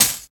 95 HAT 2.wav